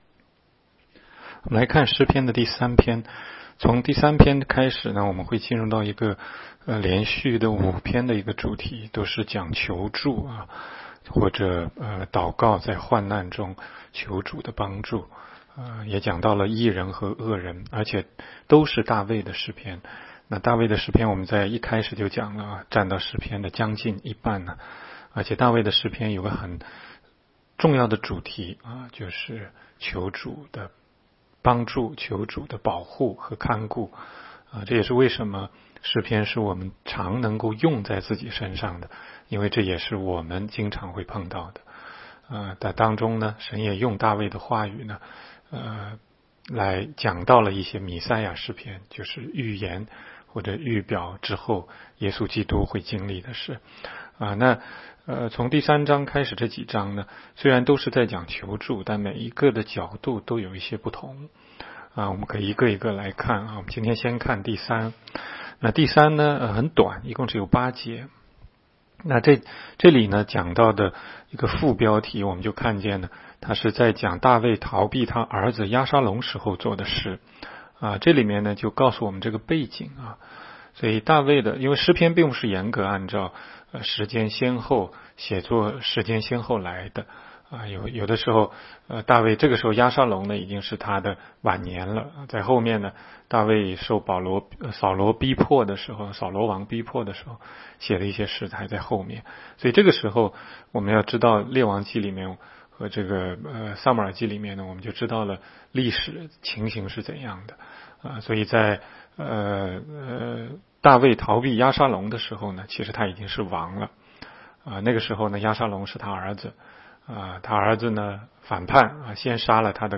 16街讲道录音 - 每日读经-《诗篇》3章